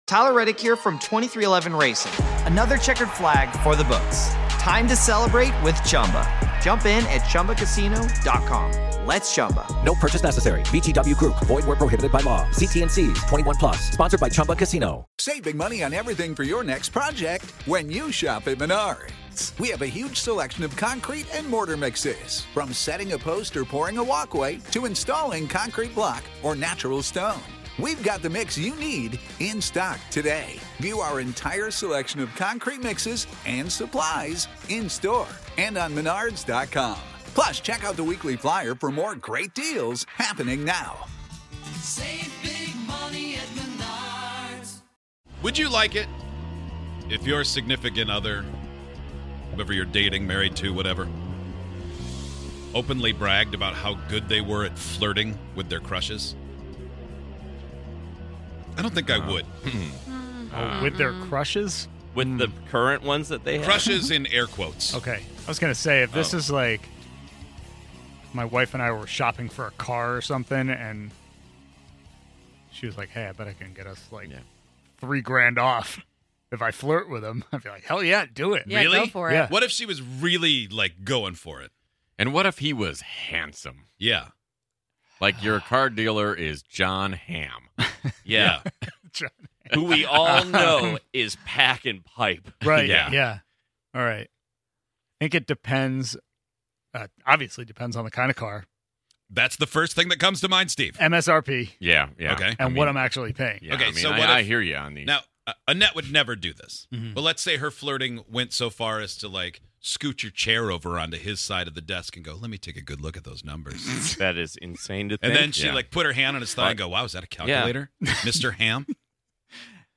On today's show, we heard from a woman who intentionally flirts with people even though she's married. Is it just flirting or is it cheating?